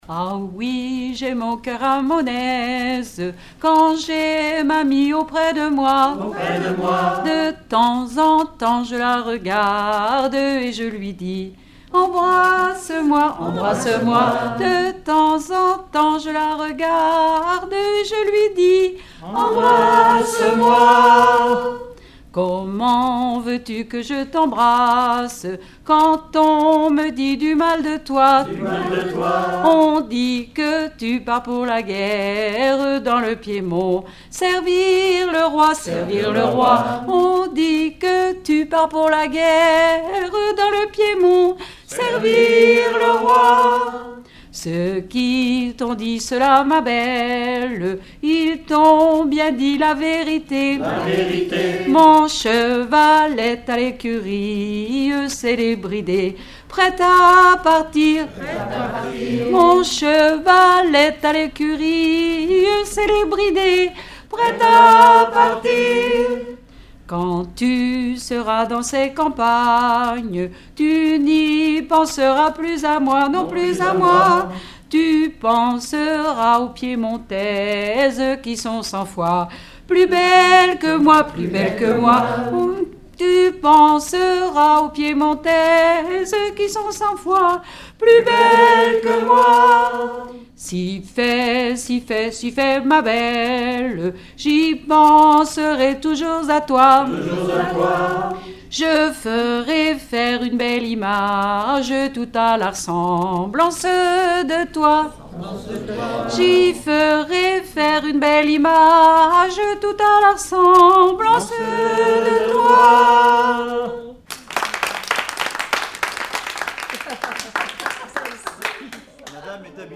Collectif de chanteurs du canton - veillée (2ème prise de son)
Pièce musicale inédite